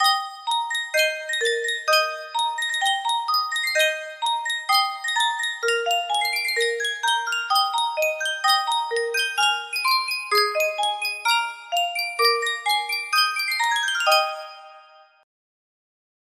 Sankyo Music Box - Head Shoulders Knees and Toes VRT music box melody
Sankyo Music Box - Head Shoulders Knees and Toes VRT
Full range 60